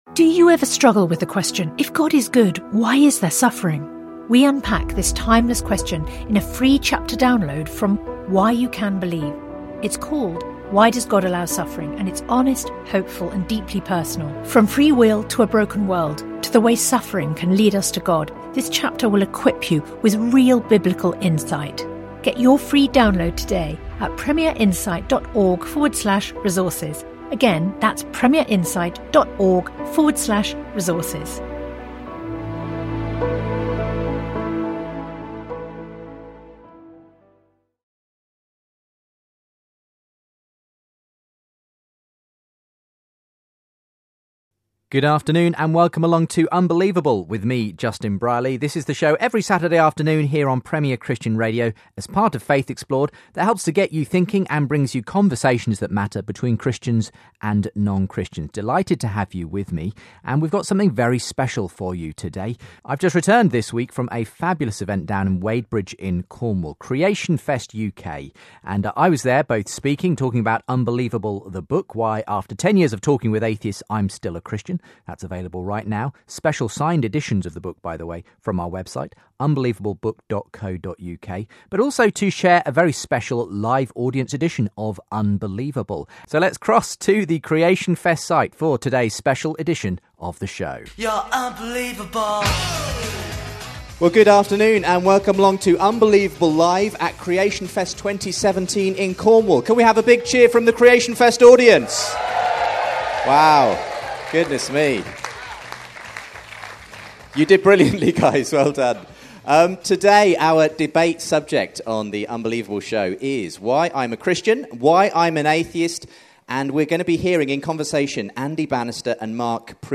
They discuss atheism, Humanism, Christianity and the search for meaning and morality. The audience also ask questions in a Q&A session.